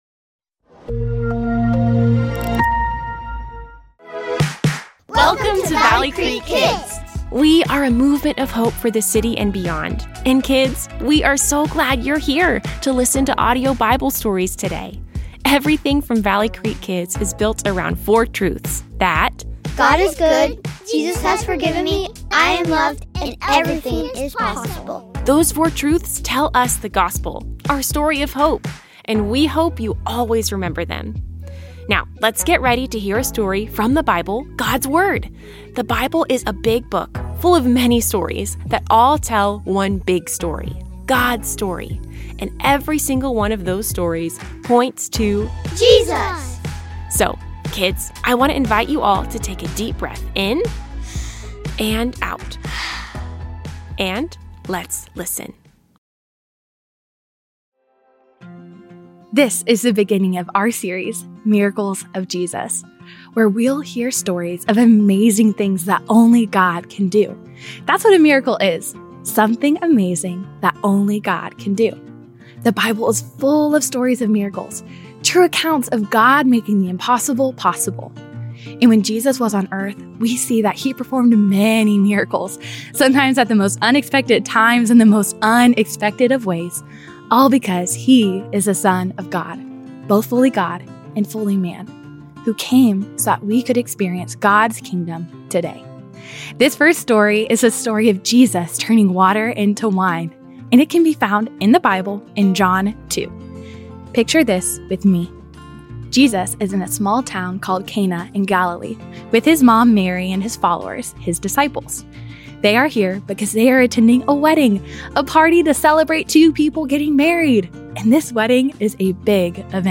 Kids Audio Bible Stories